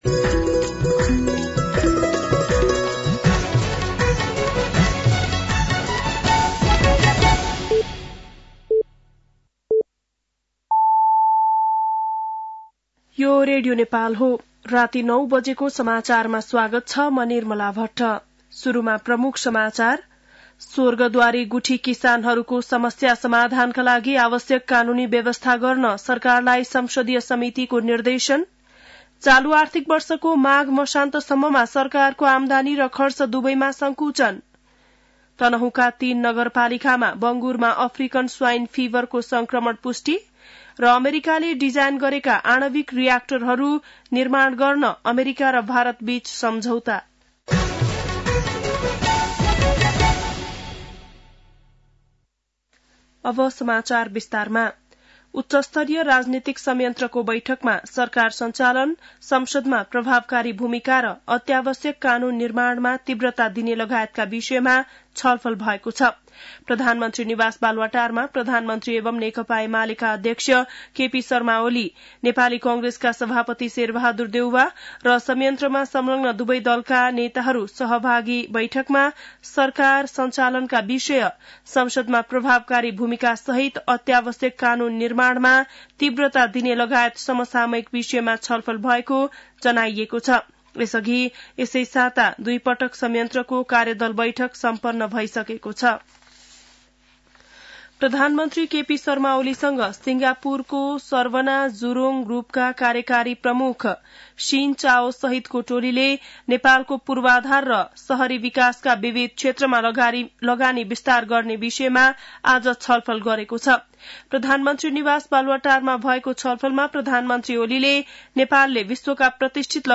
बेलुकी ९ बजेको नेपाली समाचार : ३ फागुन , २०८१